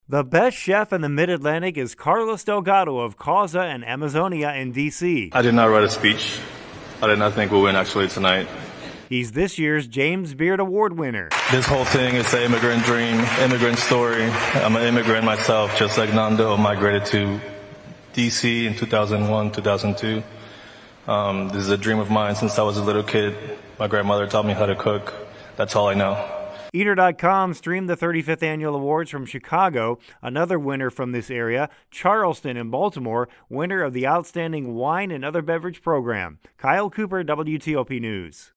Live Radio